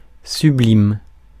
Ääntäminen
IPA: [sy.blim]